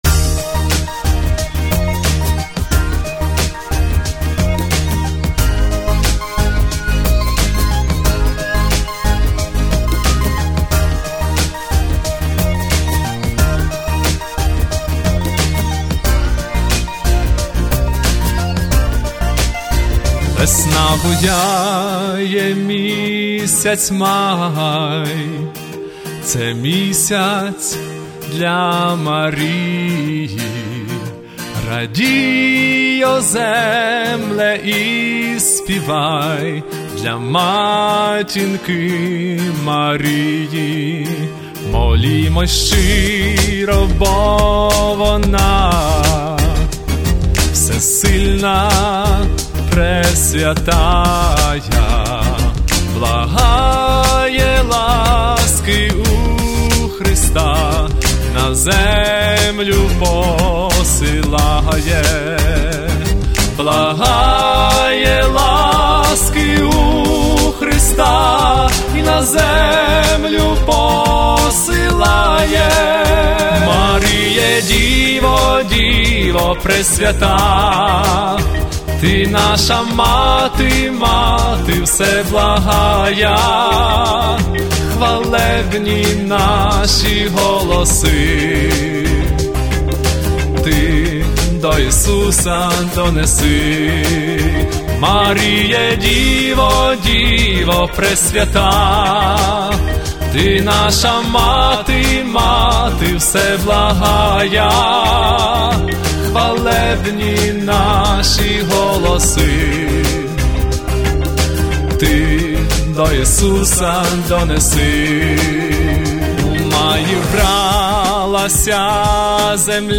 Вокально-інструментальна формація